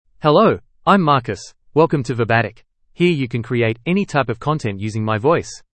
Marcus — Male English (Australia) AI Voice | TTS, Voice Cloning & Video | Verbatik AI
MaleEnglish (Australia)
Marcus is a male AI voice for English (Australia).
Voice sample
Marcus delivers clear pronunciation with authentic Australia English intonation, making your content sound professionally produced.